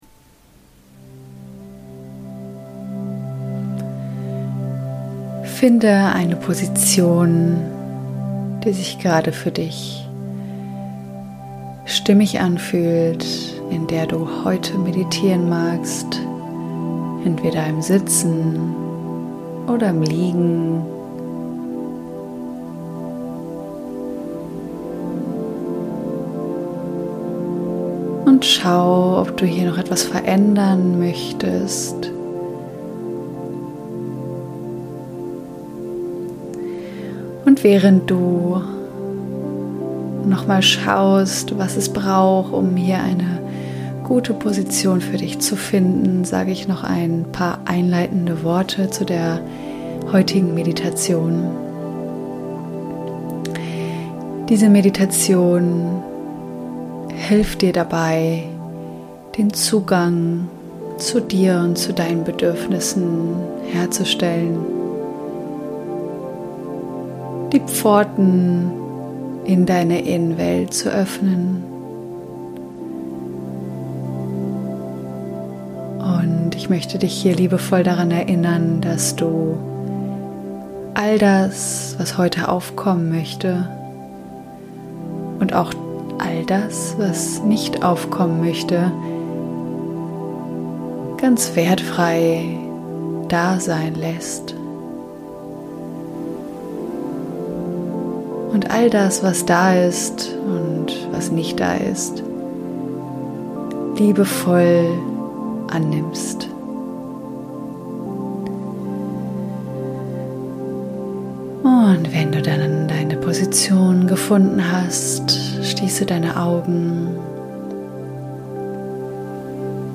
Meditation: Connecting with myself ~ Mein Leben - Mein Sein | Dein Inspirations-Podcast Podcast